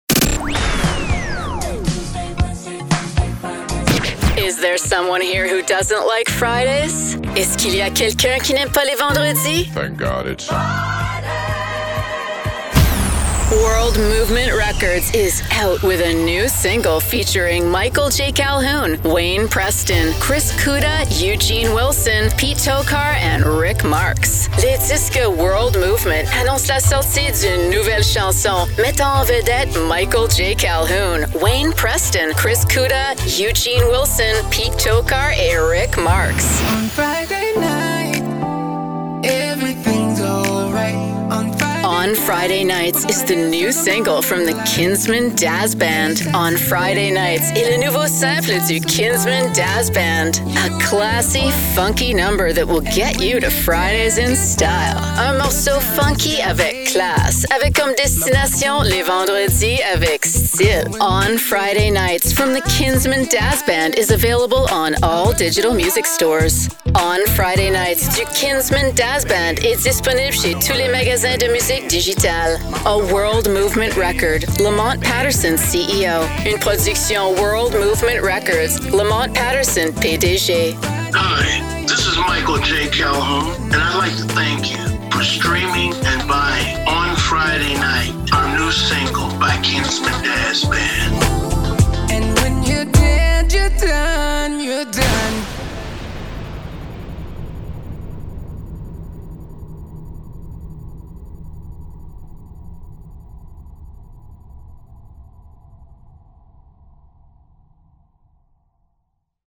Radio Commercial